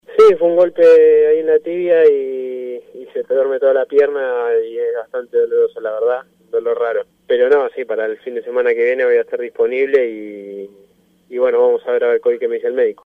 Etiquetas declaraciones